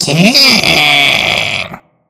Audio / SE / Cries / KINGDRA.mp3